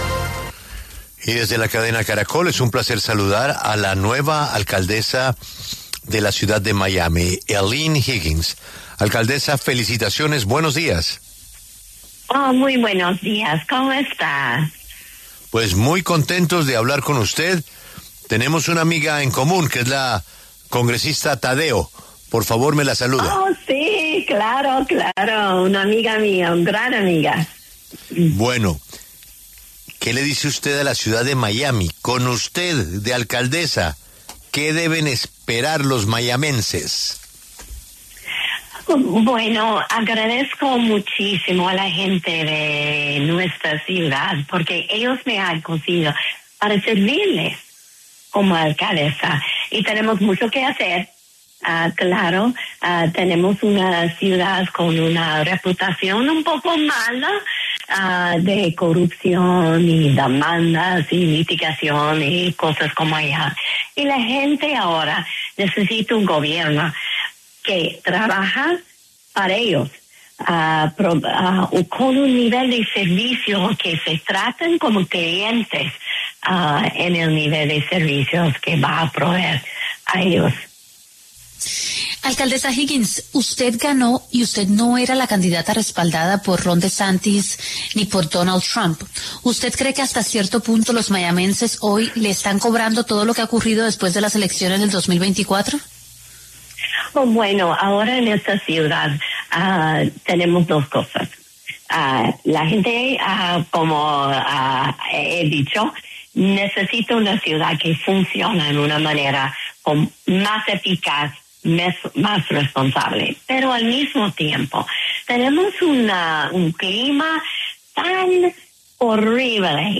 La alcaldesa electa Higgins pasó por los micrófonos de La W tras su triunfo y expuso algunas de sus posturas que pudieron llevarla a la Alcaldía.